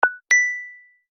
Glass.ogg